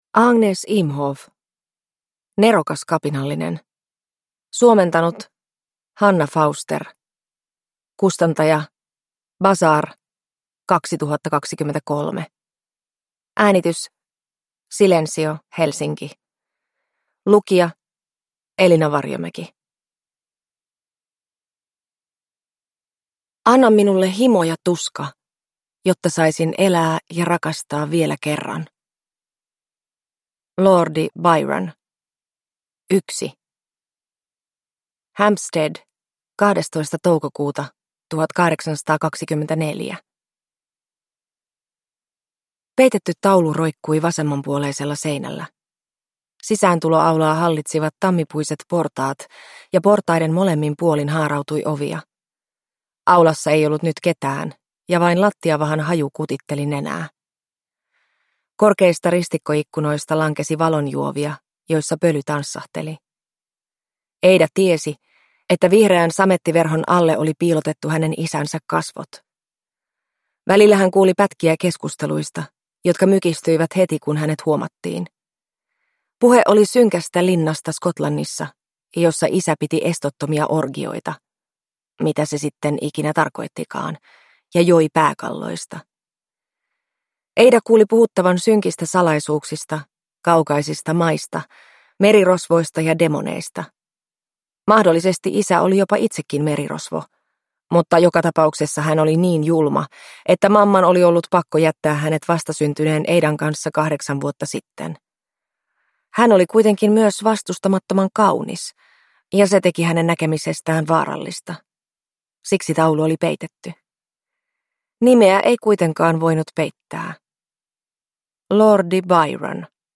Nerokas kapinallinen – Ljudbok – Laddas ner